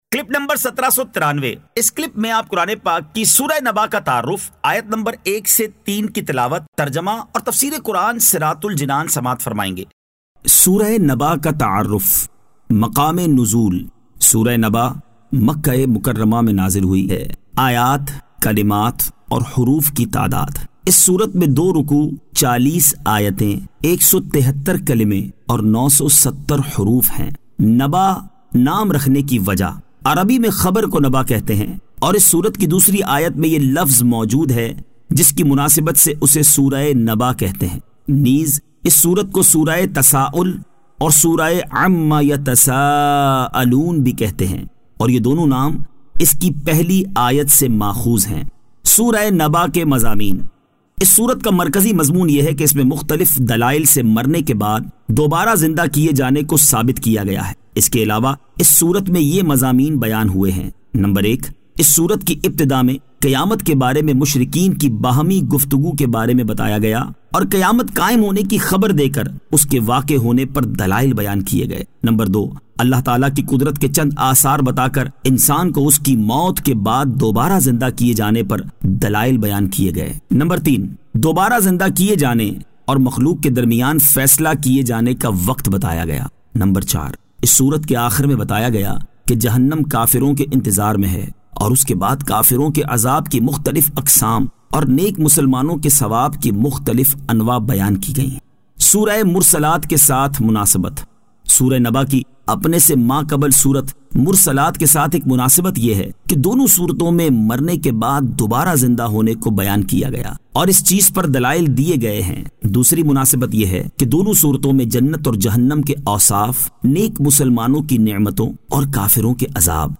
Surah An-Naba 01 To 03 Tilawat , Tarjama , Tafseer